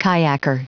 Prononciation du mot kayaker en anglais (fichier audio)
Prononciation du mot : kayaker